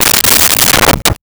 Dresser Drawer Opened 02
Dresser Drawer Opened 02.wav